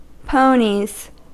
Ääntäminen
Ääntäminen US Haettu sana löytyi näillä lähdekielillä: englanti Käännöksiä ei löytynyt valitulle kohdekielelle. Ponies on sanan pony monikko.